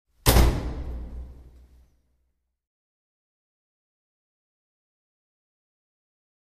Metal, Door | Sneak On The Lot
Heavy Metal Door Close With Reverb